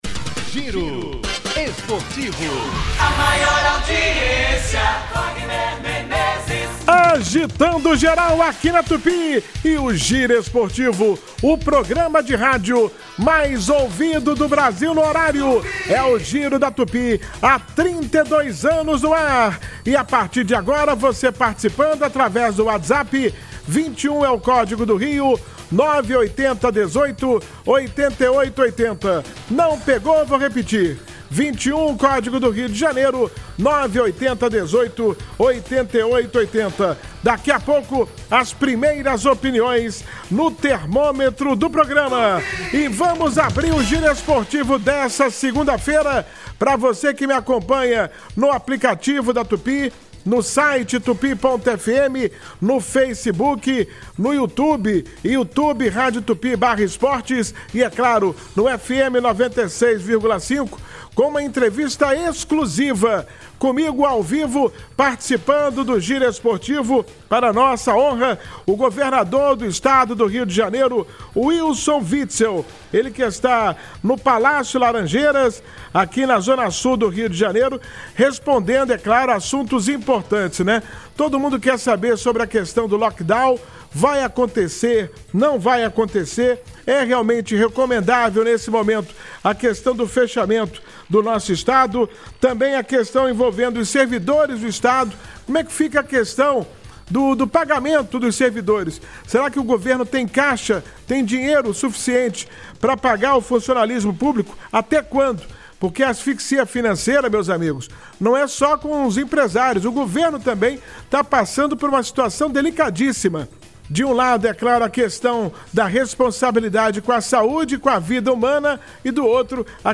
O chefe do executivo carioca concedeu entrevista exclusiva à Super Rádio Tupi e comentou sobre a saída de Edmar Santos da secretaria de Saúde do estado do Rio.